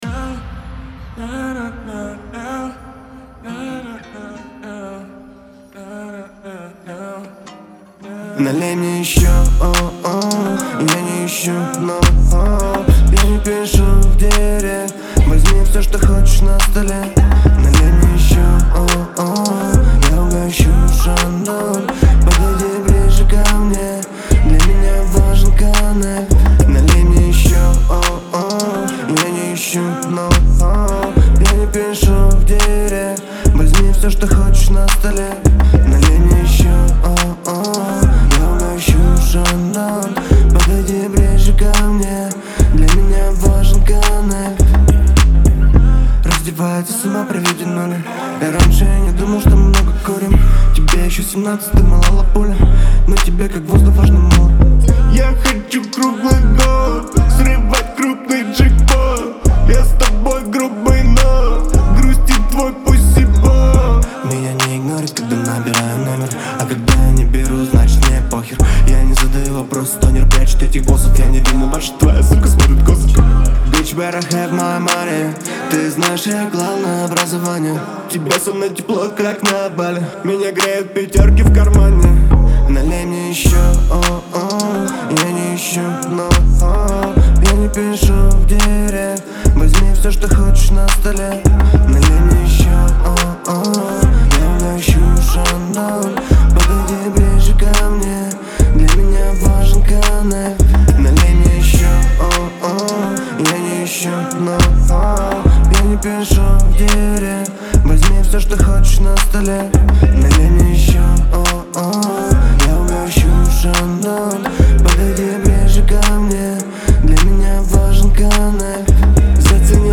это динамичная композиция в жанре хип-хоп